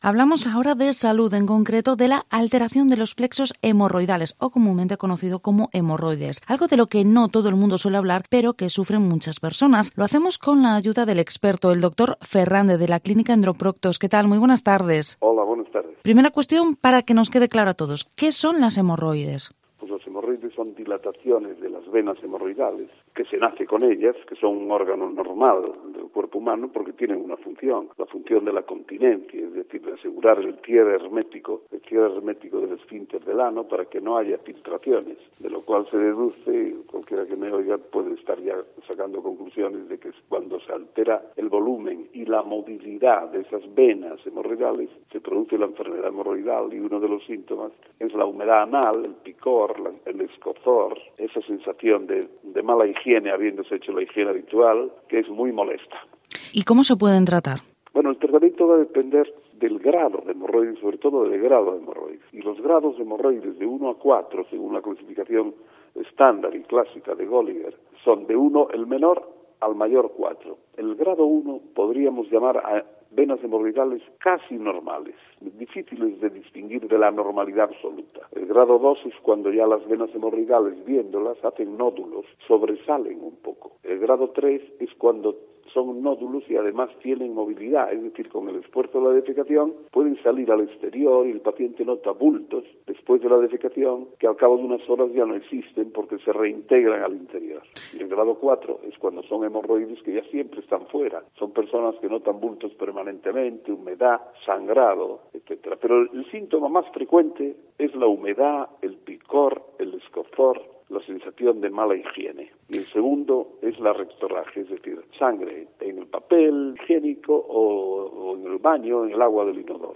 Vigo Entrevista ¿Qué tratamientos existen para tratar las Hemorroides?